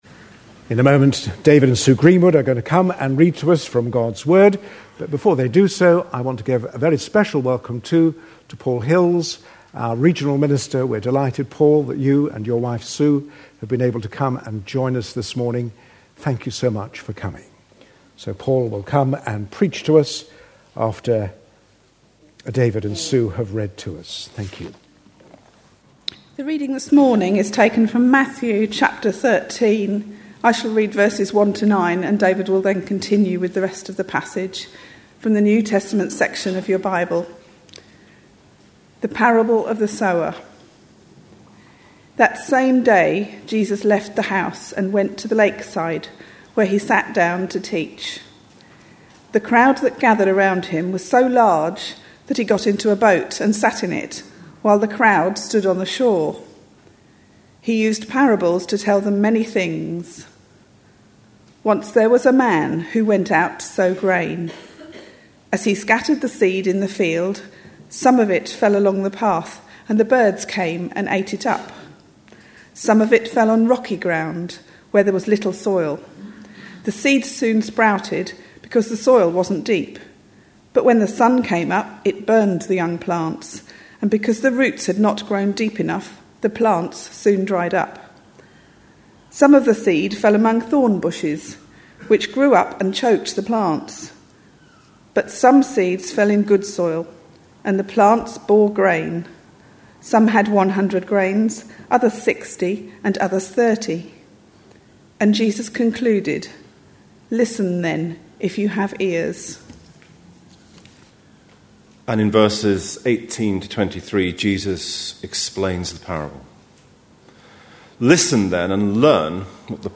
A sermon preached on 12th September, 2010, as part of our Parables of Matthew series.